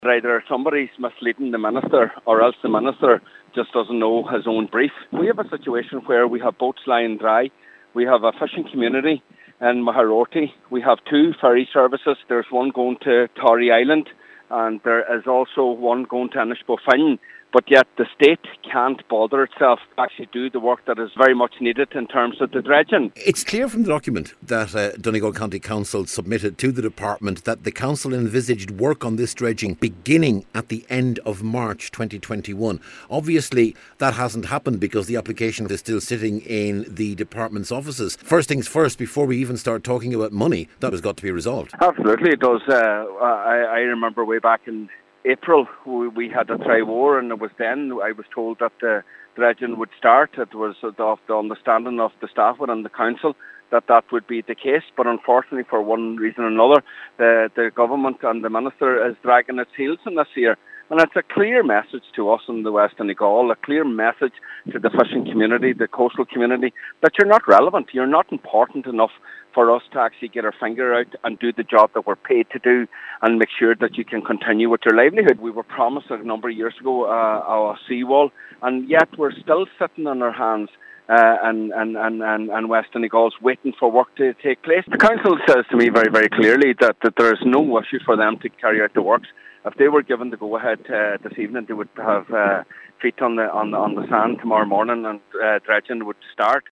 Local Councillor Michael Cholm MacGiolla Easbuig says this is another indication of how the government regards West Donegal……………